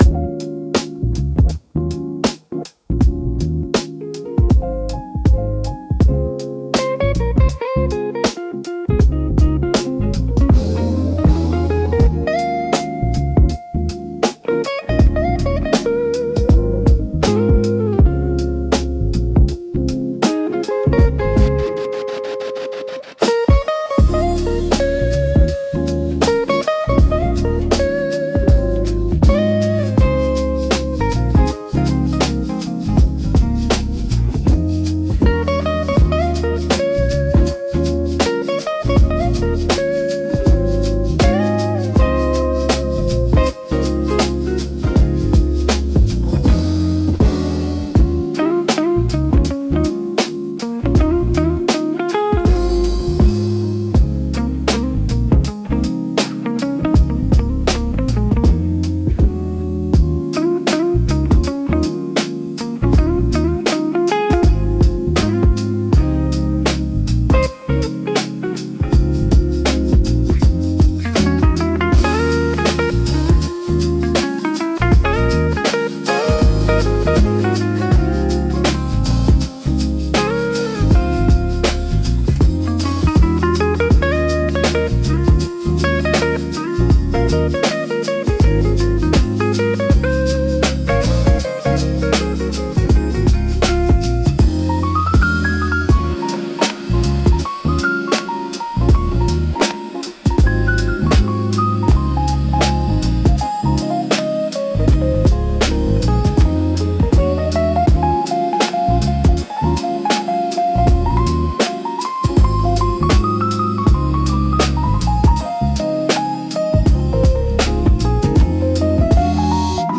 Chill Lo-Fi Beat